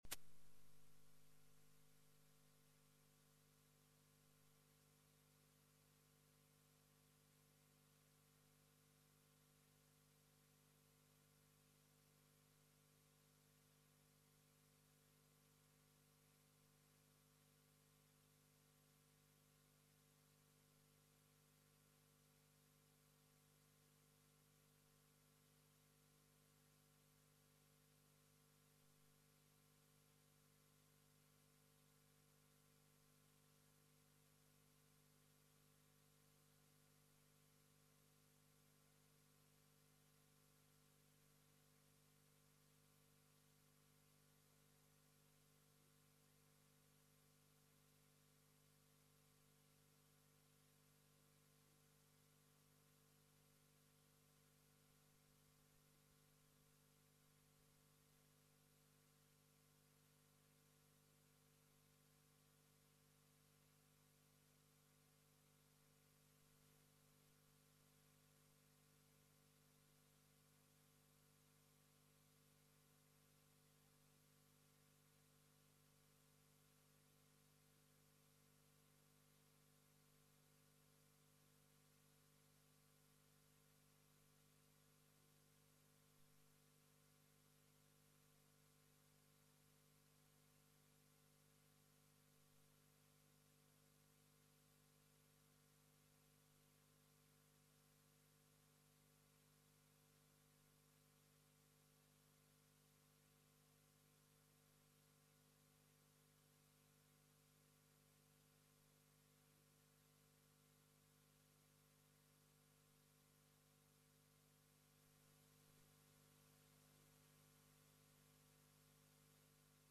Selbsterkenntnis - Fluch oder Segen? ~ Predigten der LUKAS GEMEINDE Podcast